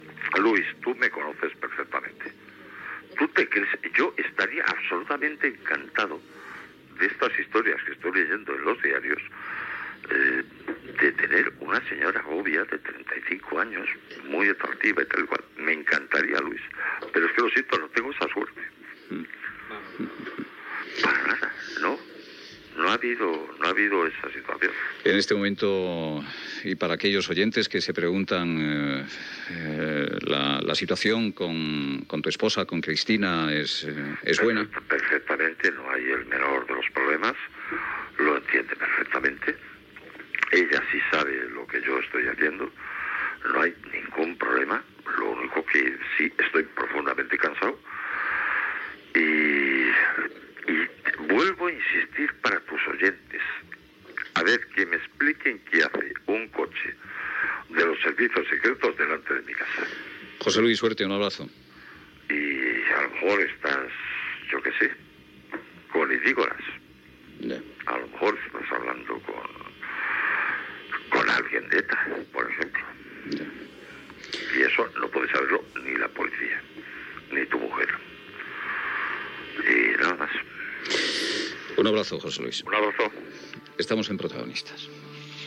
Explicacions de Martín Prieto i comiat.
Info-entreteniment